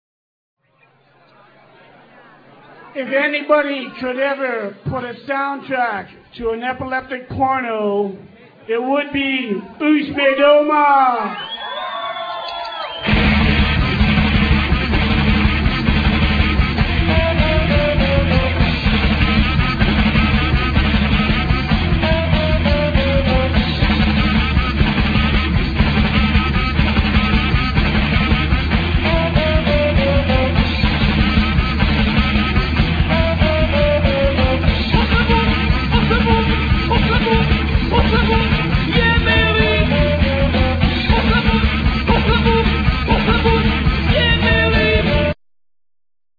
Vocals,Guitar,Keyboard
Saxophone,Vocals
Bass,Vocals
Drums